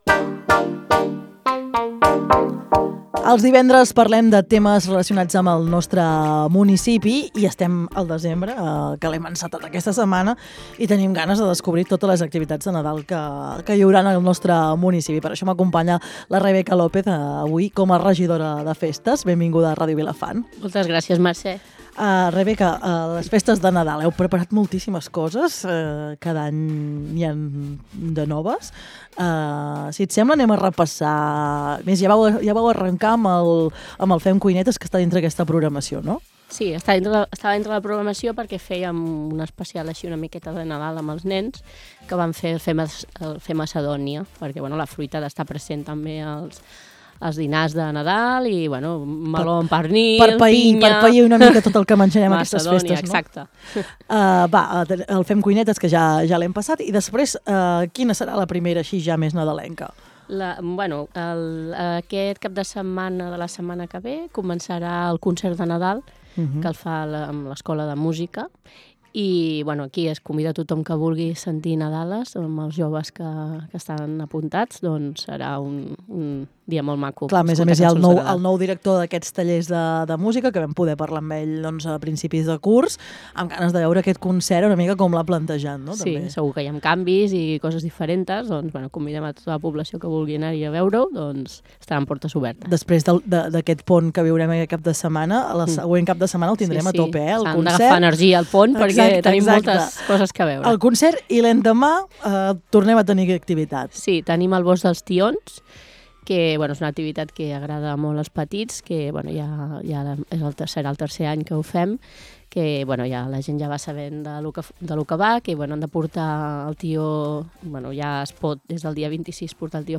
Avui, a Les Veus del Matí, hem conversat amb Rebeca López, regidora de Festes de l’Ajuntament de Vilafant, per conèixer de primera mà totes les activitats nadalenques que ompliran el municipi de llum, música i tradició.
LVDM - ENTREVISTA - ACTIVITATS DE NADAL REBECA LOPEZ 5 DESEMBRE 25~0.mp3